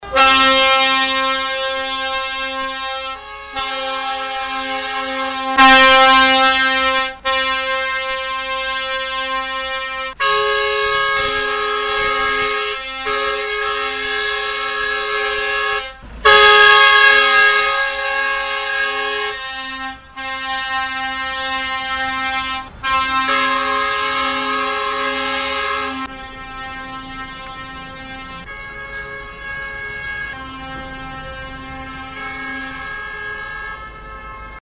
AMBIANTA-Claxoane-protest.mp3